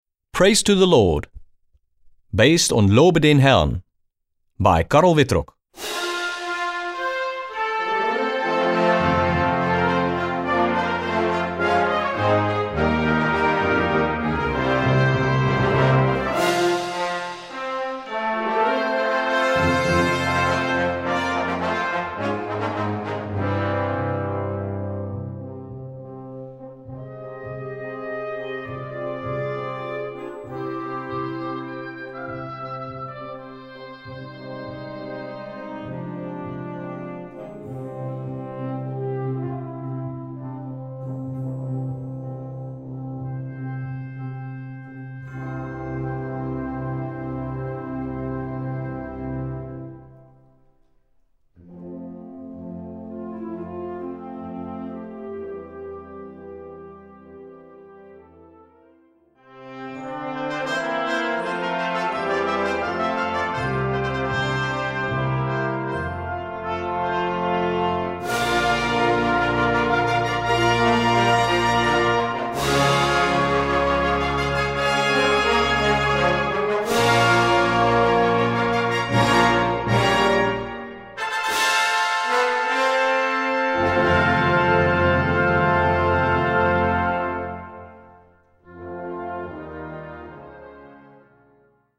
Gattung: Konzertantes Kirchenwerk
Besetzung: Blasorchester